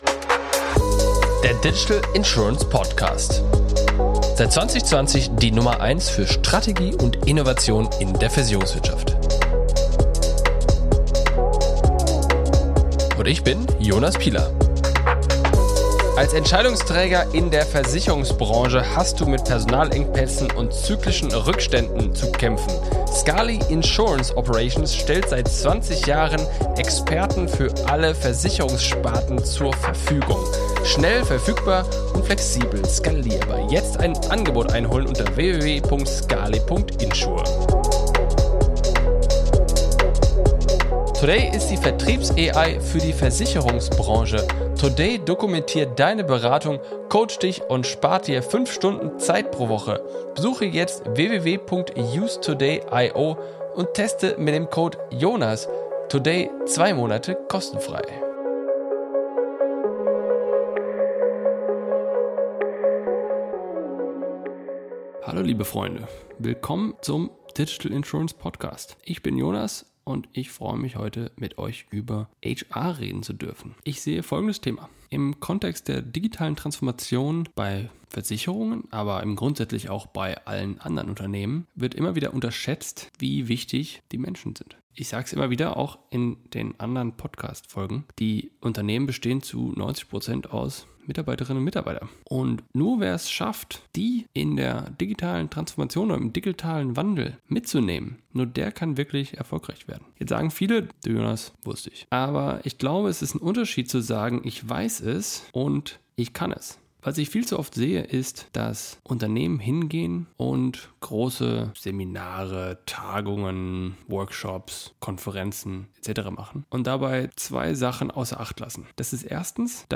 Darüber spreche ich in diesem Monolog. Ich erläutere, warum ich mit denjenigen vorangehen muss, die wirklich Freude an dem Wandel haben.